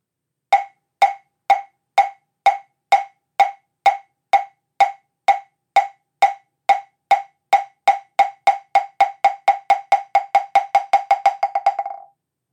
Moktak Korean Drum
This Korean moktak drum is a lovely piece of craftsmanship, carved from beautiful apricot wood. It comes with a wooden striker. The sound of this instrument is crisp and excellent.
Moktak-Large.mp3